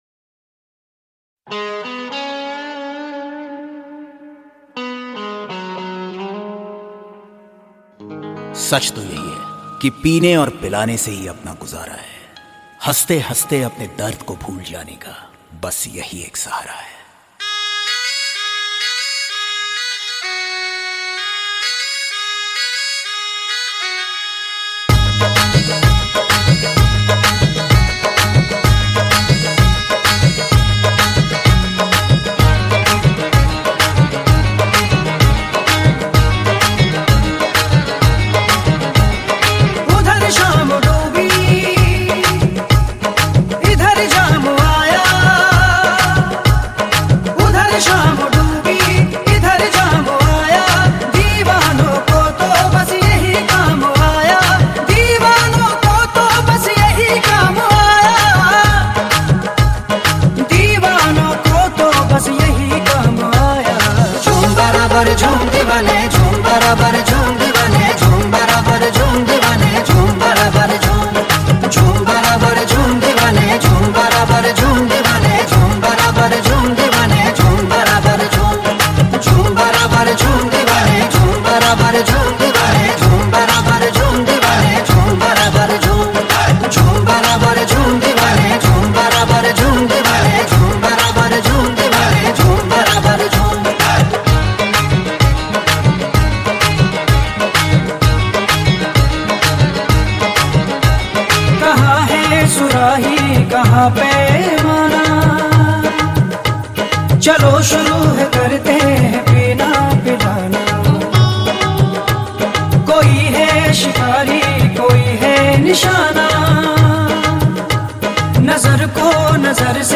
Hindi Pop